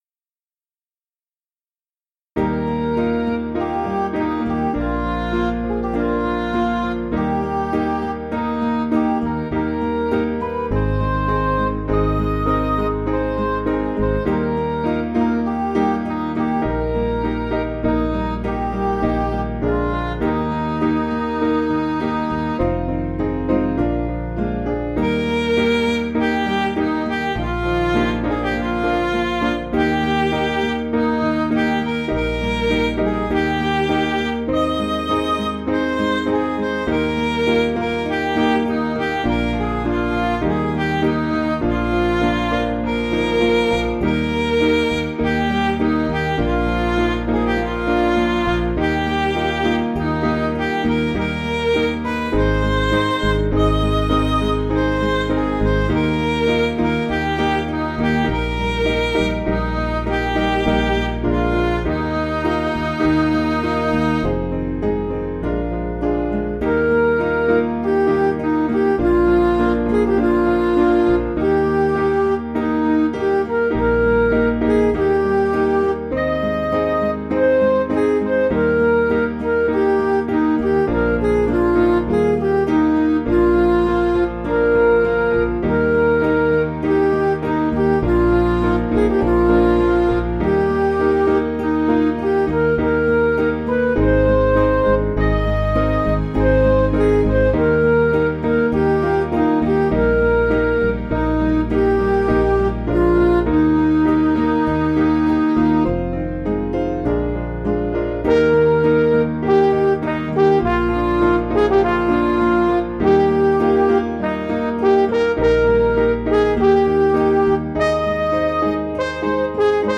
Piano & Instrumental
(CM)   3/Eb
Midi